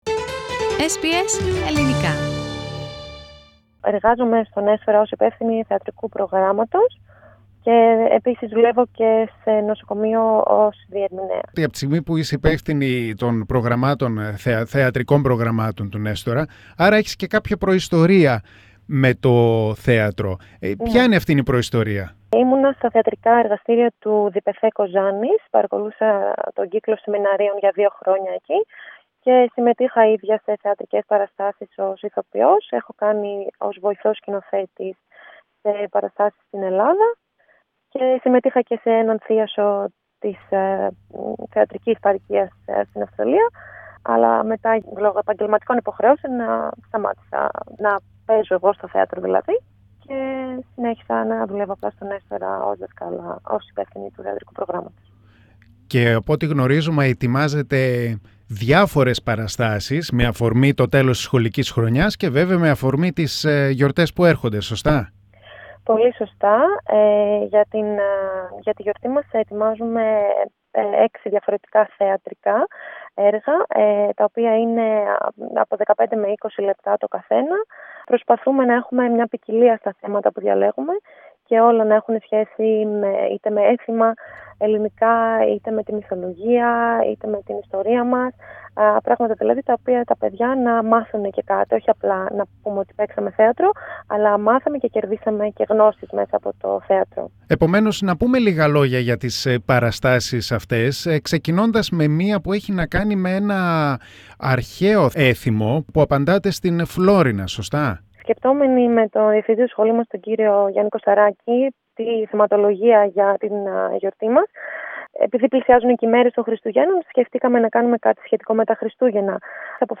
The interview is on in Greek.